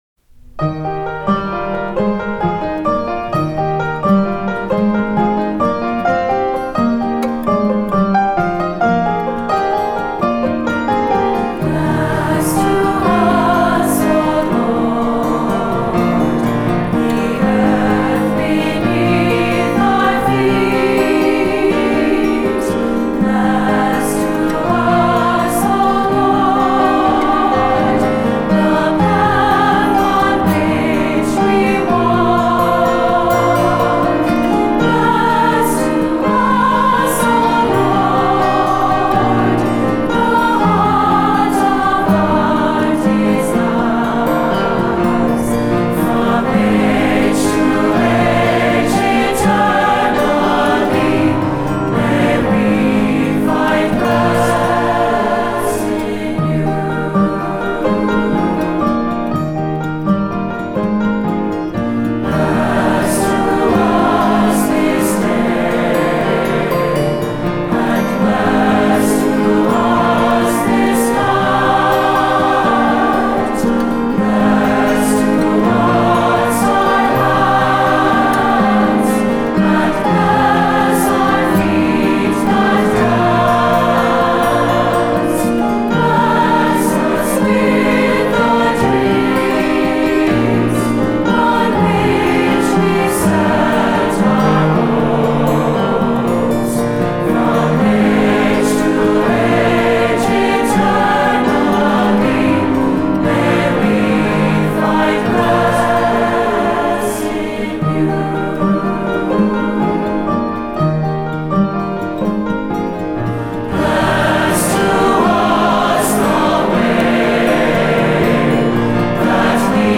Voicing: 3-part Choir